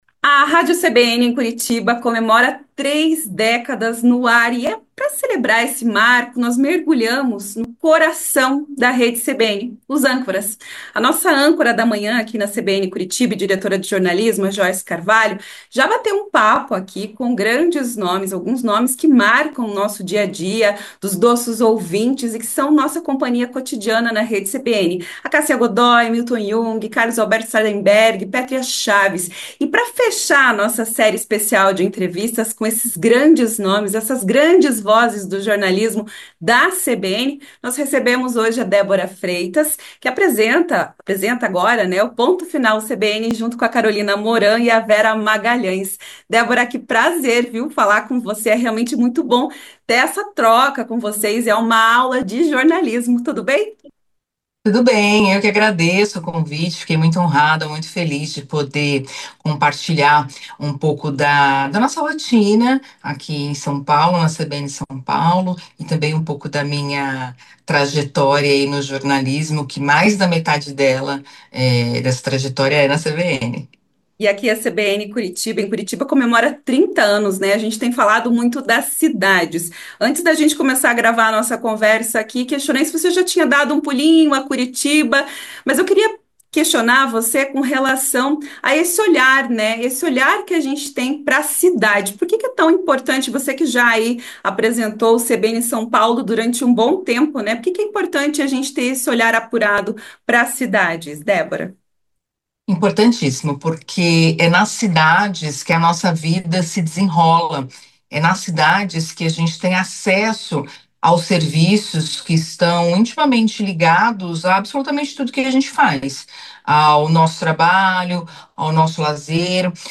entrevista à CBN Curitiba